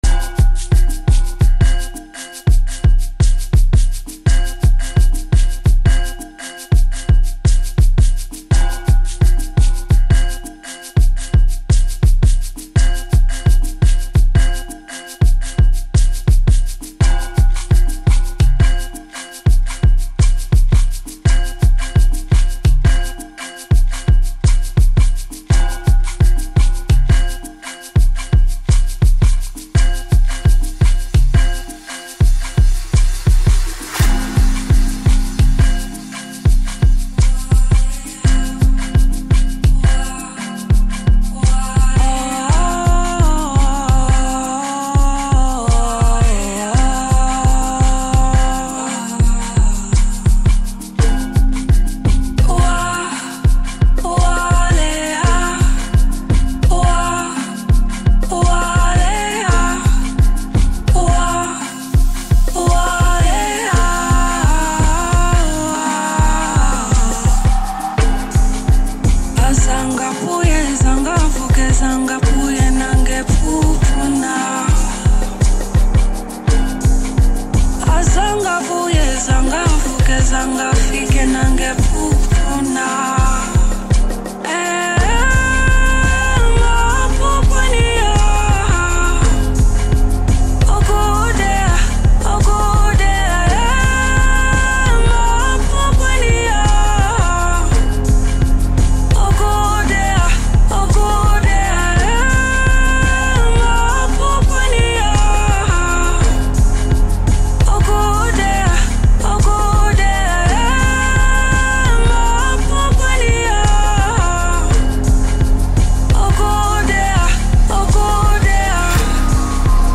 South African singer